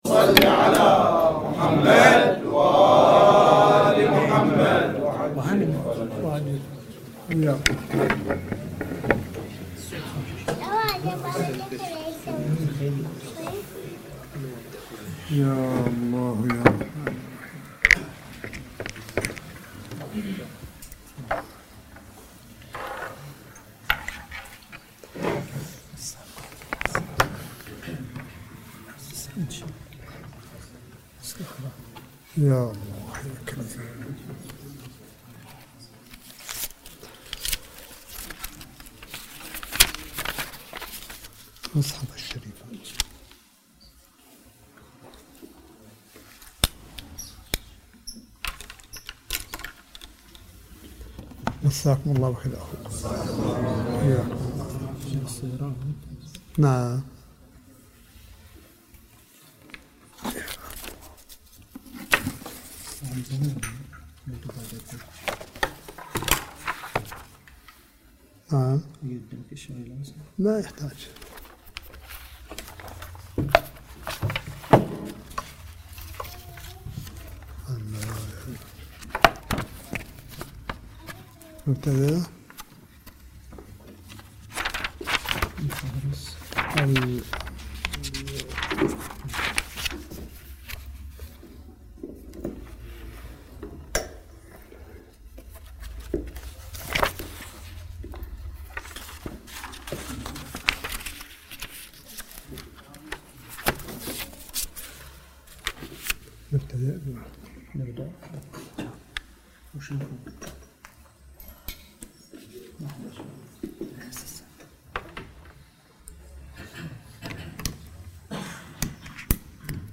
ملف صوتي للحديث القرآني لسماحة آية الله الشيخ عيسى أحمد قاسم حفظه الله بقم المقدسة – 1 شهر رمضان 1440 هـ / 08 مايو 2019م